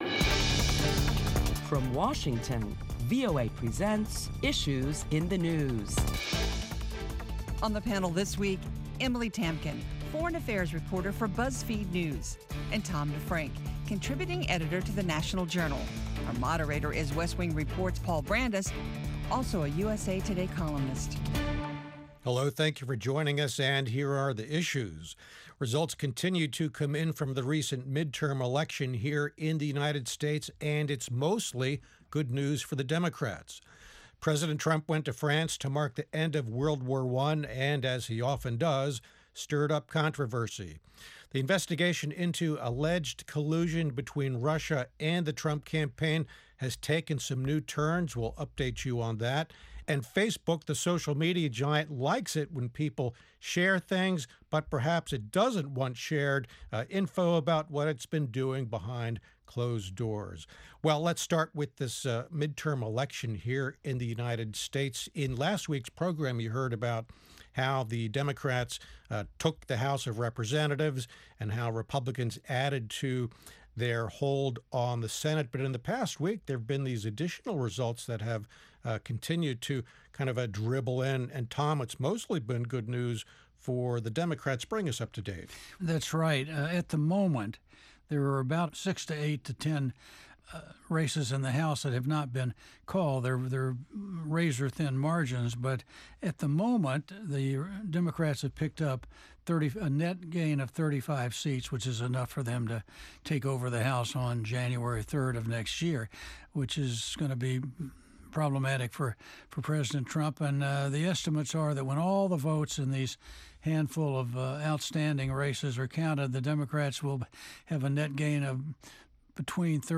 Listen as top Washington correspondents discuss the Democrat's influence on President Trump's next two years as their majority widens in the House of Representatives.